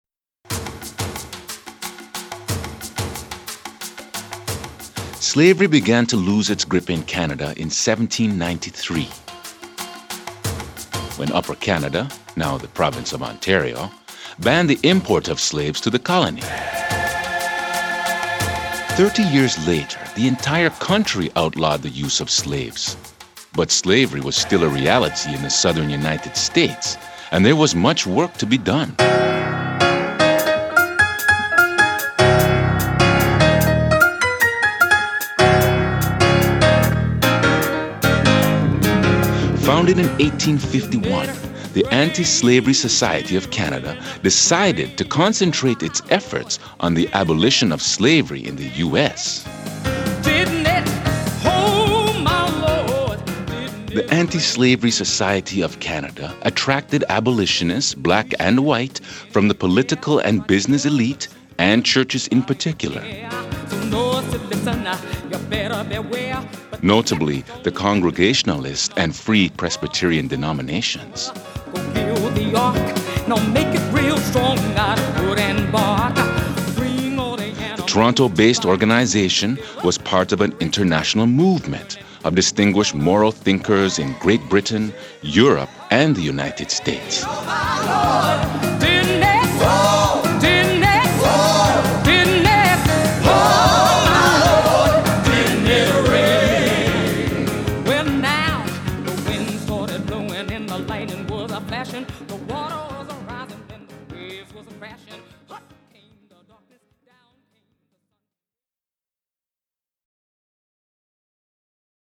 Narrator: